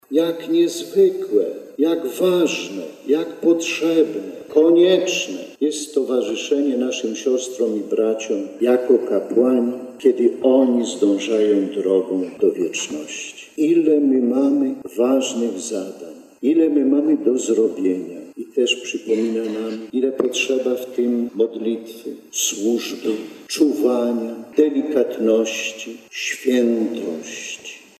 Mówiąc godności ludzkiej osoby i wartości powołania bp Kamiński zwrócił uwagę na znaczenie posługi kapłańskiej.